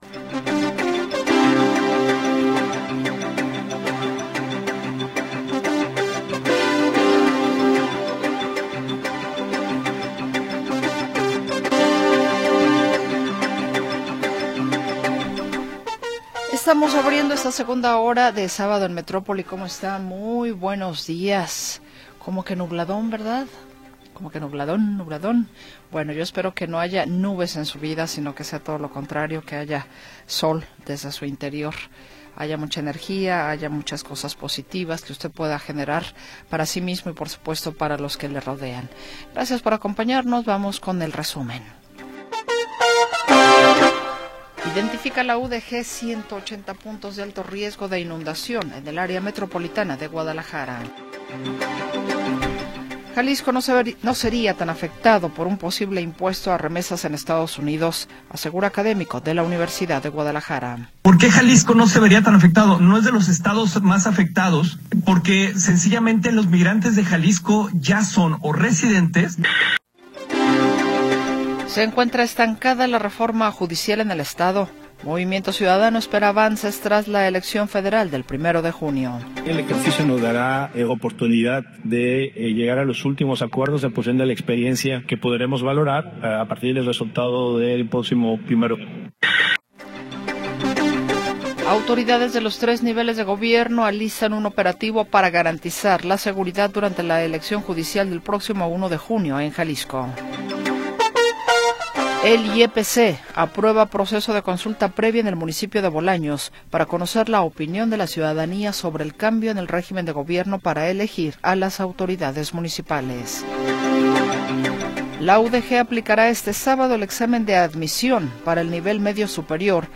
Segunda hora del programa transmitido el 24 de Mayo de 2025.